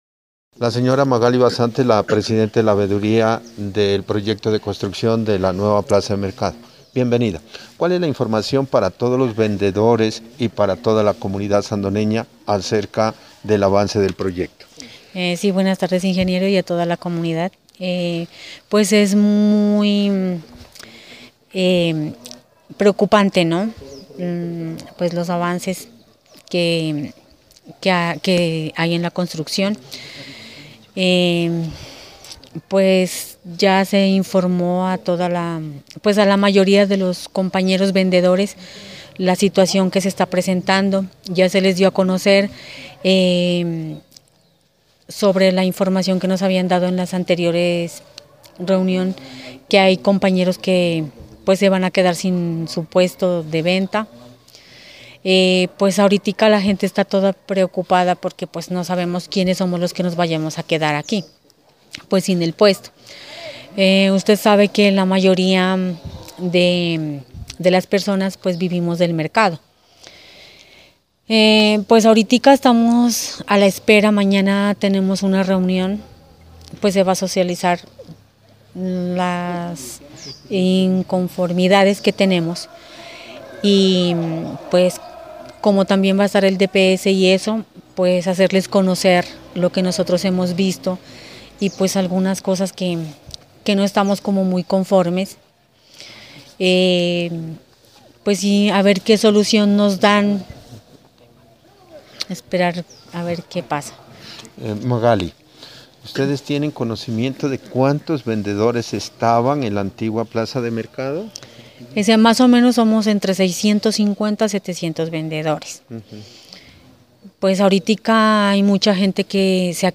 Entrevistas: